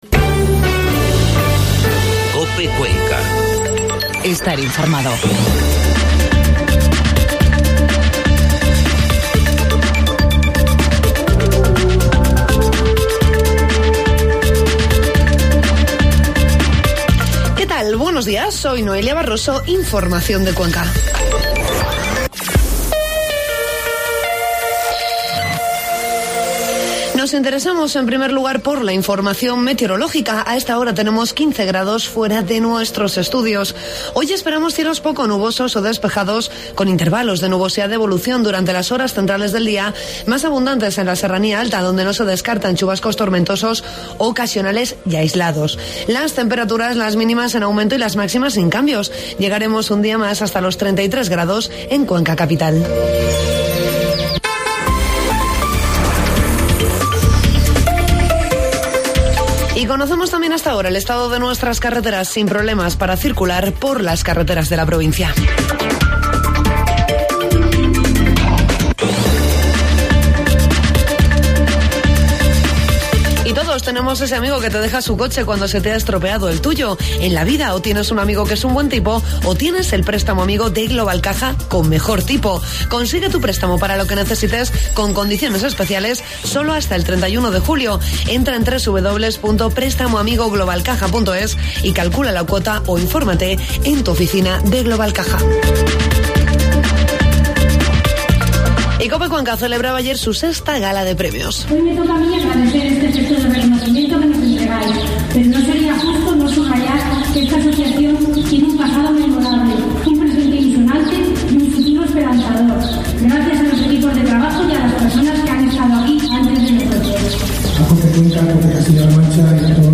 Informativo matinal COPE Cuenca 22 de junio